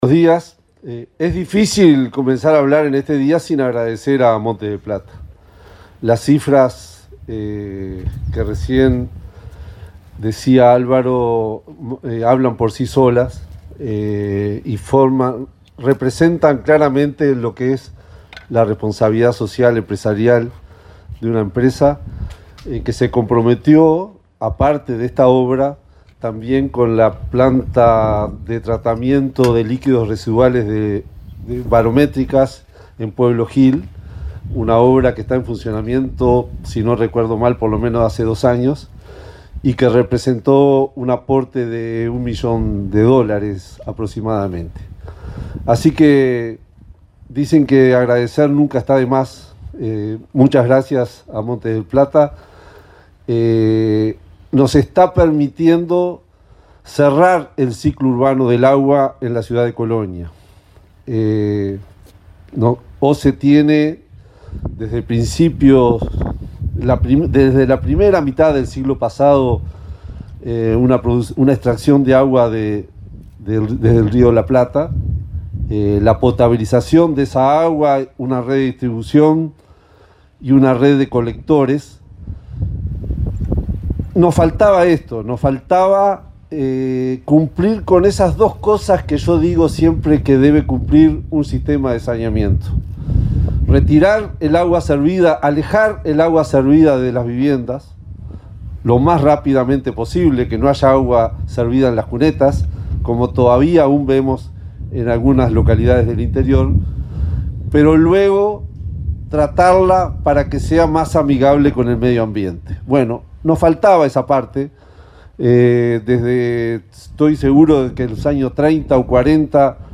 Palabras del presidente de OSE, Raúl Montero